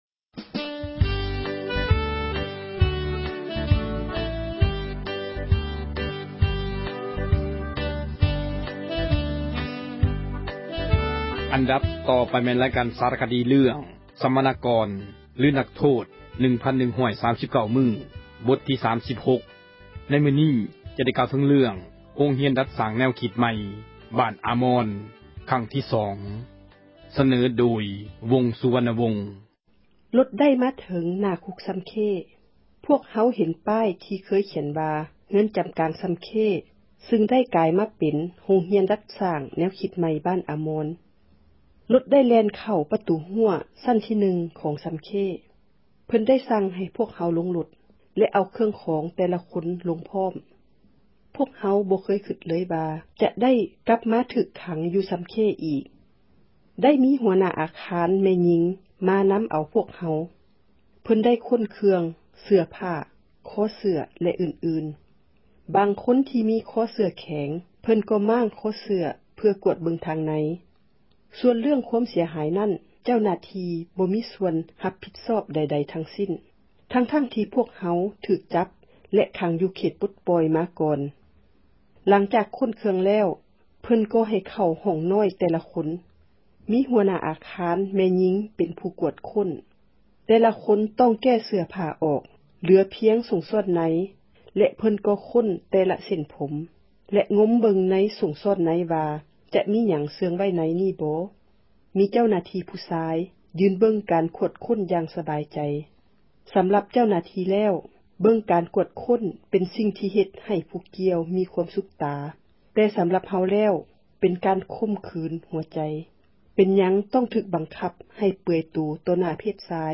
ຣາຍການ ສາຣະຄະດີ ເຣື້ອງ ”ສັມມະນາກອນ ຫຼື ນັກໂທດ 1,139 ມື້“ ບົດທີ 36. ໃນມື້ນີ້ ຈະໄດ້ ກ່າວເຖິງ ເຣື້ອງ ໂຮງຮຽນ ດັດສ້າງ ແນວຄິດ ໃໝ່ ທີ່ບ້ານ ”ອາມອນ” ຄັ້ງທີ່ ສອງ.